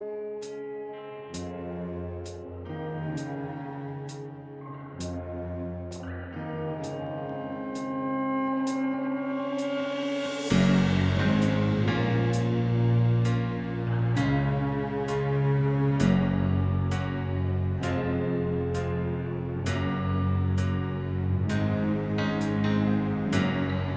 Minus Main Guitar Pop (2010s) 4:05 Buy £1.50